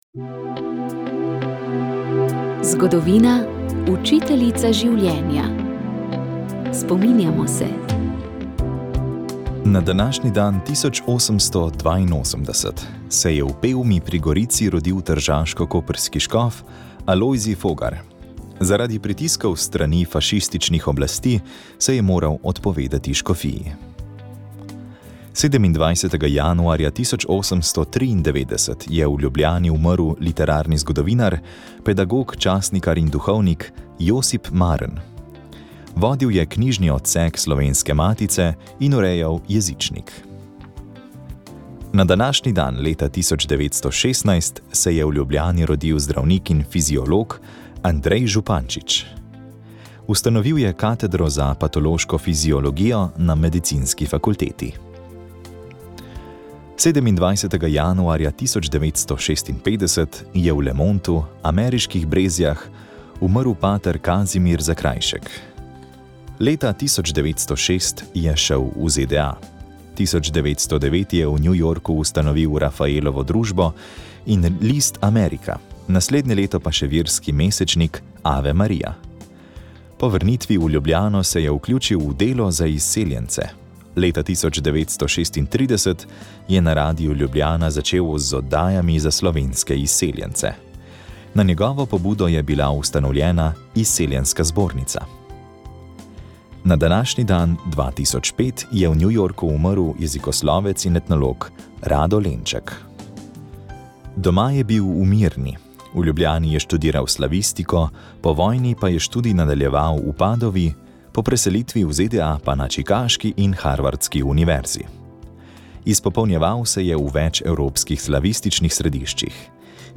Poročali smo, kako gre romarjem, ki so ob 800-letnici Sončne pesmi vzeli v roke popotno palico in se peš odpravili iz Goričkega v Piran. Približno na polovici poti jih je naš mikrofon ujel pri kapucinih v Štepanji vasi.